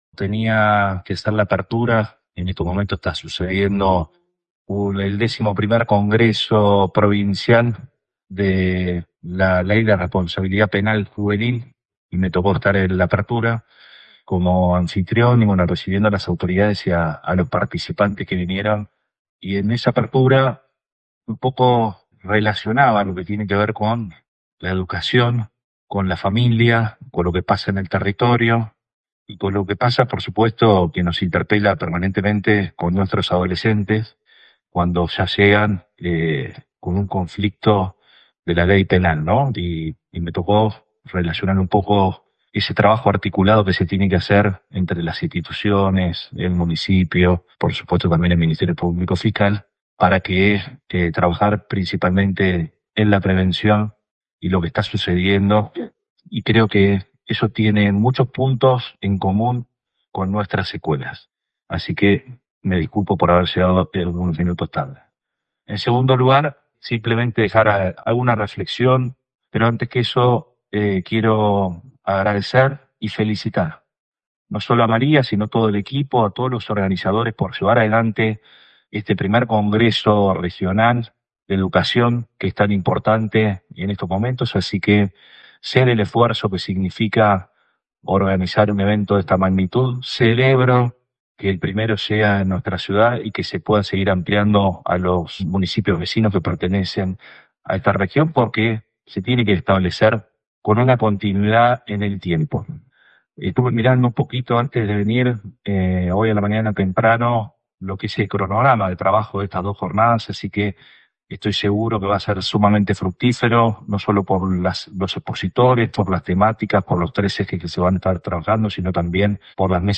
Arturo Rojas brindó sus palabras en la apertura del 1º Congreso de Educación de la Región 20 que tiene a Necochea como anfitrión y defendió enfáticamente la educación pública.